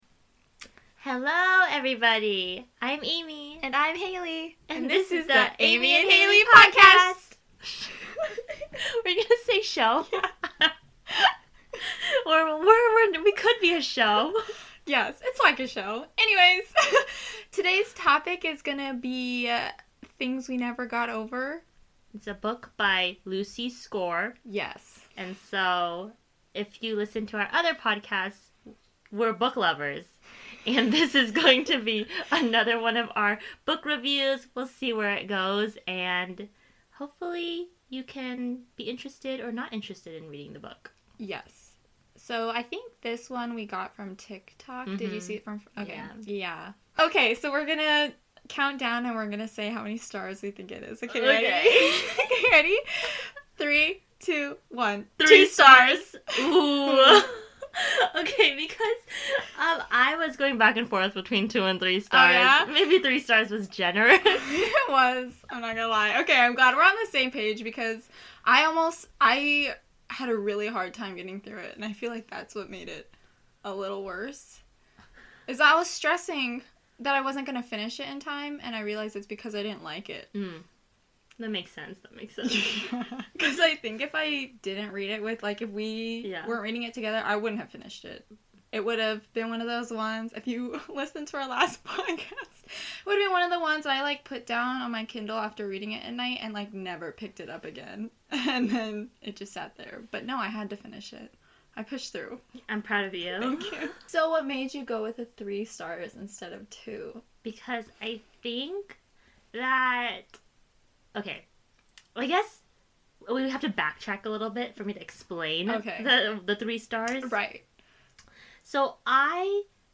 Book review alert!! Today's episode centers on Things We Never Got Over by Lucy Score.